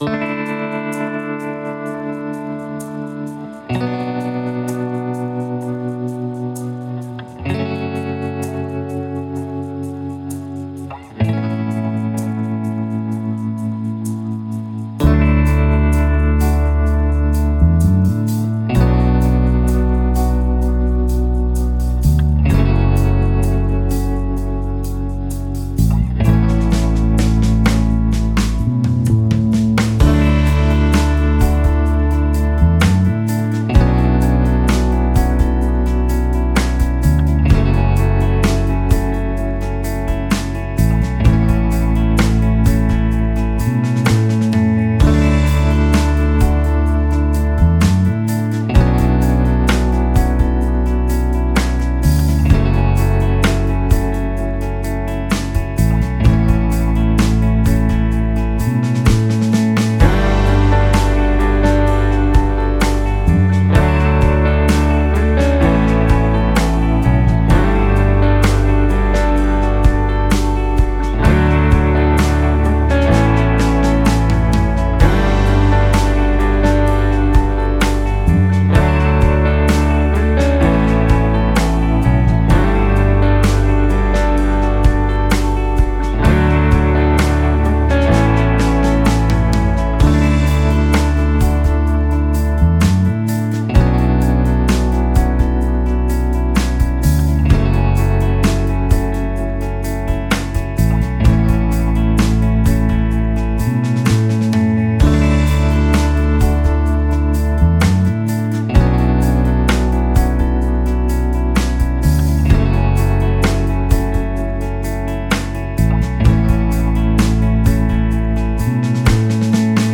D Major Chill Out Backing Track